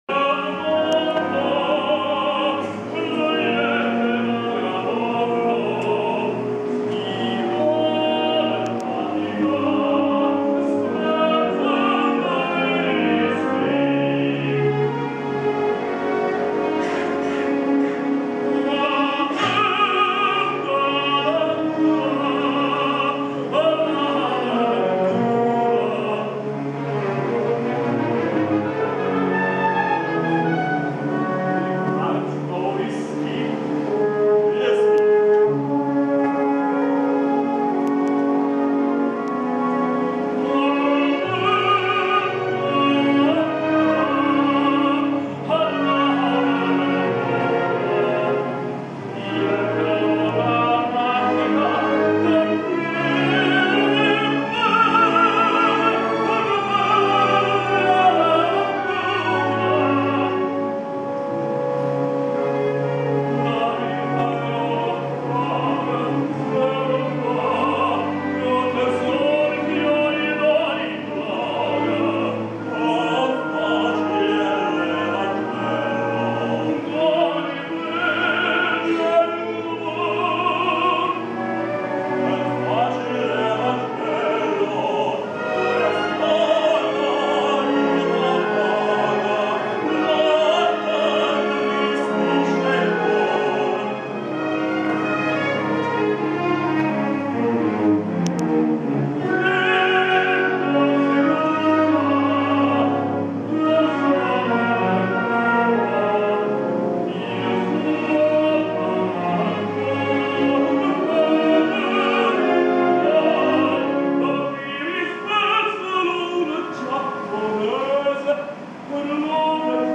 Opernsänger, Tenor